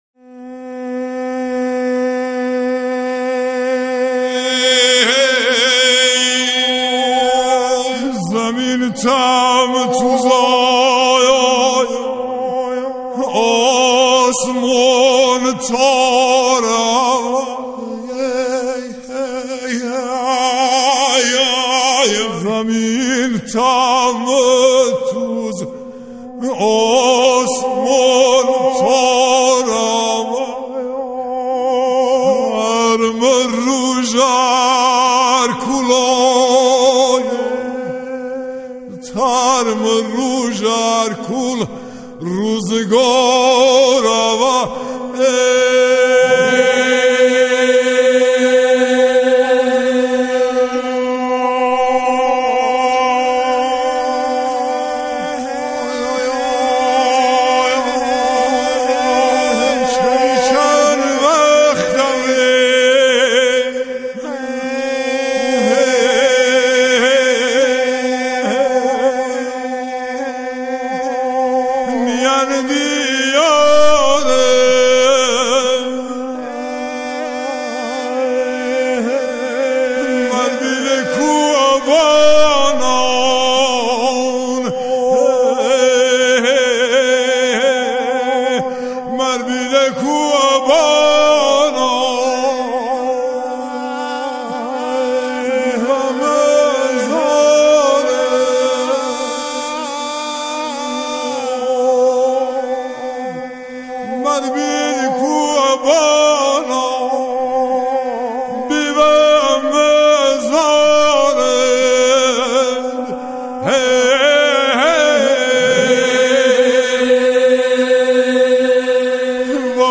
آکاپلا
آنها در این قطعه، شعری را با گویش لری اجرا می‌کنند.